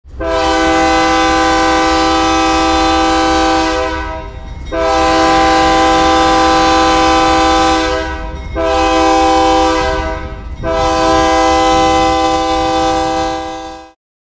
Railroad horn at crossing